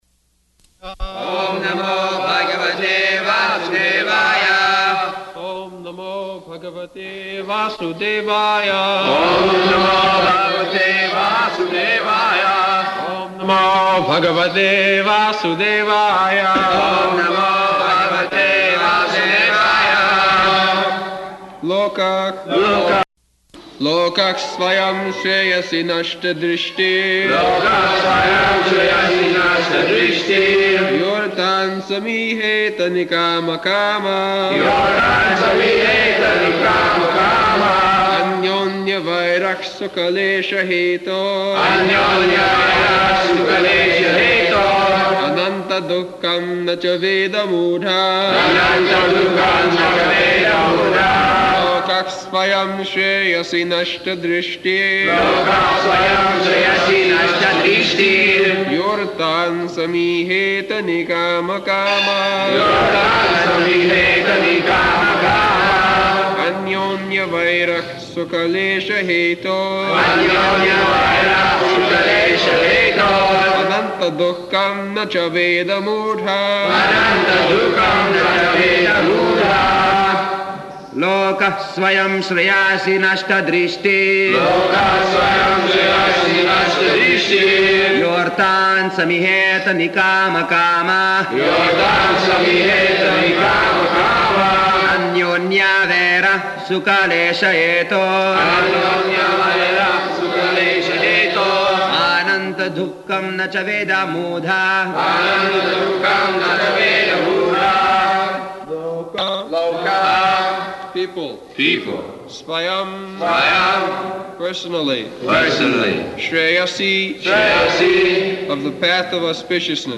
November 4th 1976 Location: Vṛndāvana Audio file
[devotees repeat] [leads chanting of verse, synonyms, etc.]